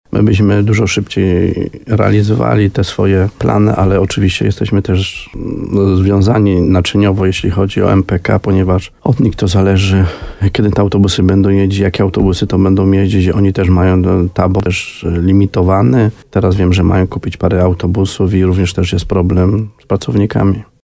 Tym razem chodzi o linie 22, która ma jeździć najprawdopodobniej już w lutym do miejscowości Trzetrzewina. Wójt Stanisław Kuzak, w programie Słowo za Słowo na antenie RDN Nowy Sącz, podkreślał, że chciał, aby trasa była dostępna wcześniej.